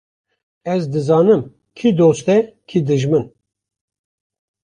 Prononcé comme (IPA)
/doːst/